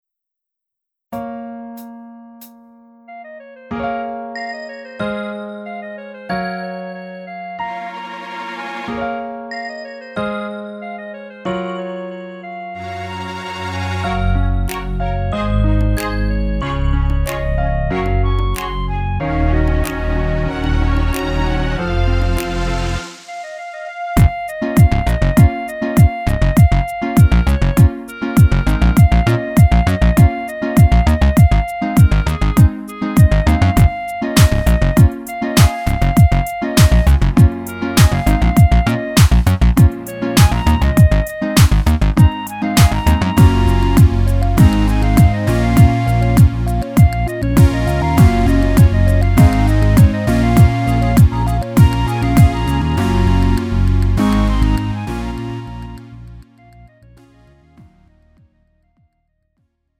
음정 원키 3:07
장르 가요 구분 Lite MR